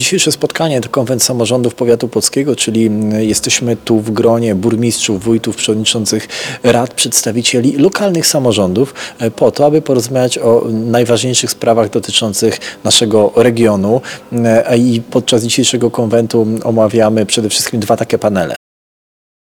V Konwent Samorządów Powiatu Płockiego
– Przekazał Sylwester Ziemkiewicz, Starosta Płocki.